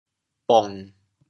潮州 cua2 ling3 bong1 潮阳 cua2 ling3 bong1 潮州 0 1 2 潮阳 0 1 2